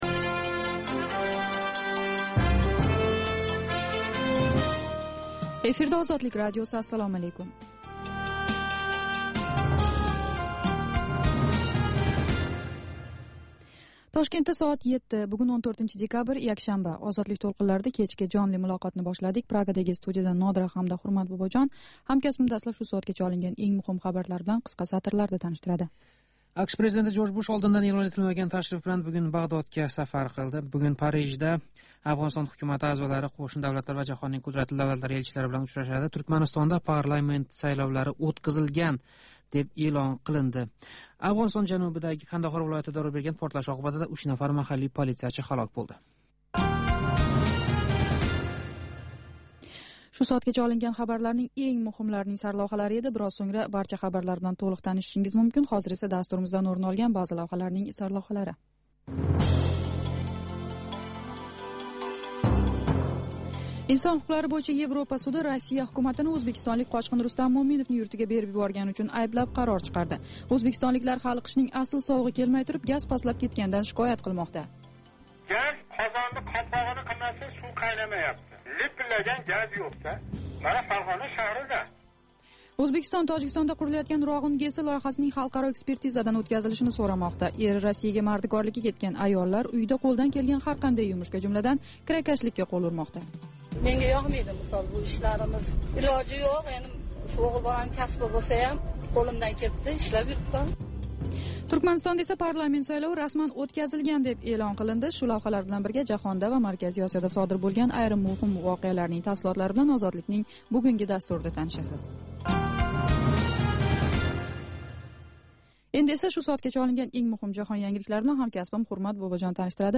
Озодлик радиосининг ўзбек тилидаги кечки жонли дастурида куннинг энг муҳим воқеаларига оид сўнгги янгиликлар¸ Ўзбекистон ва ўзбекистонликлар ҳаëтига доир лавҳалар¸ Марказий Осиë ва халқаро майдонда кечаëтган долзарб жараëнларга доир тафсилот ва таҳлиллар билан таниша оласиз